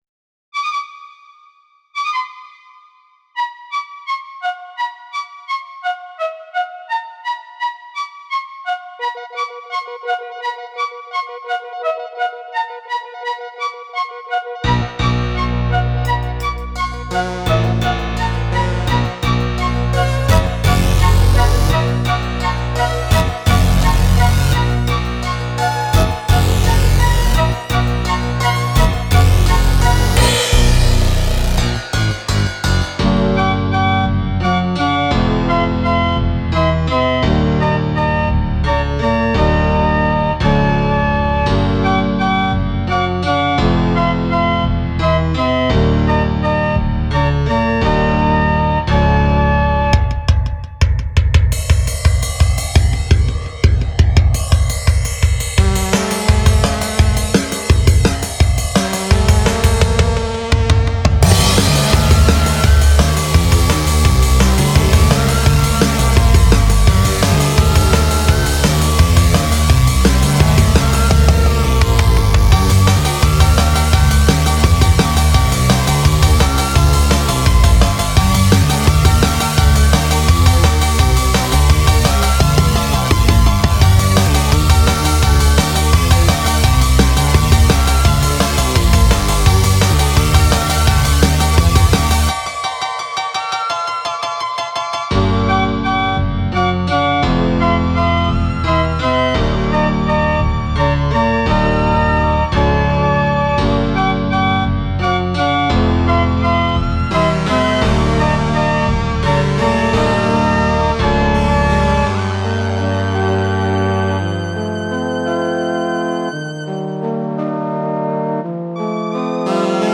Original Instrumental Song - Monotonous